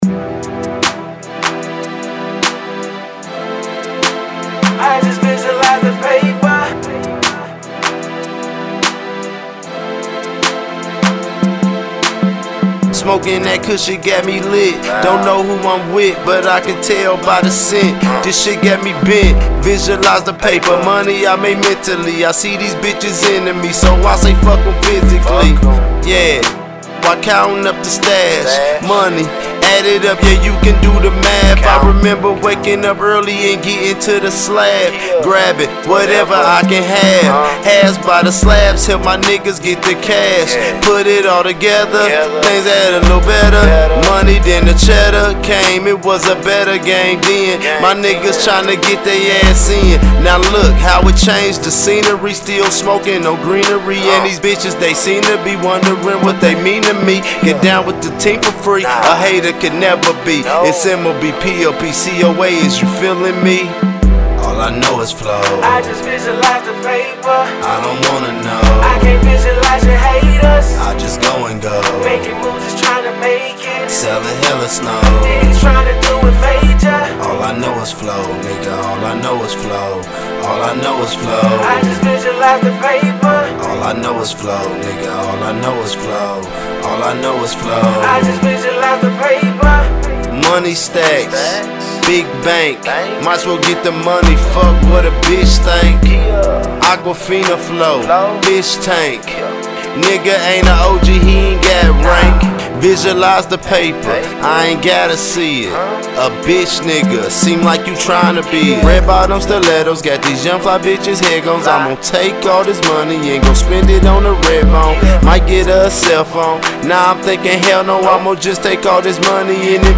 Hiphop
Description : Raw Gritty Ohio Music!!!